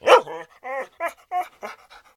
bdog_panic_1.ogg